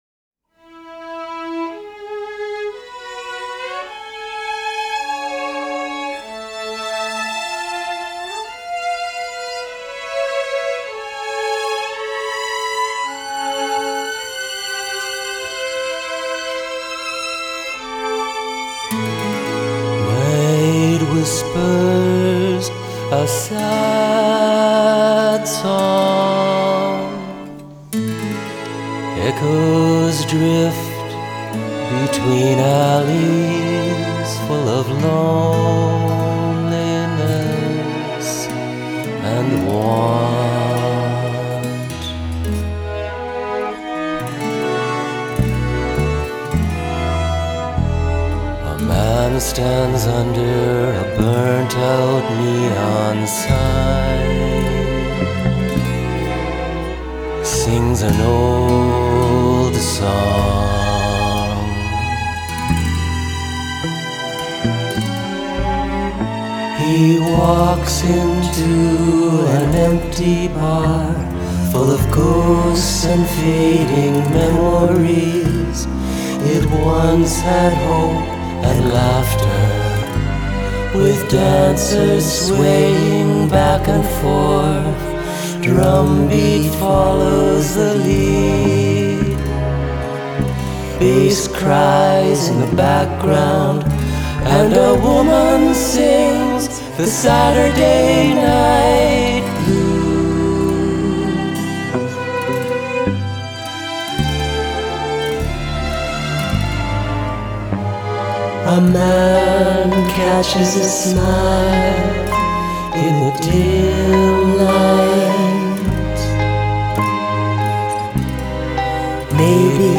Recorded in Winnipeg in June 2022
vocals/guitar/keyboards
violins
viola
violoncello
fretless electric bass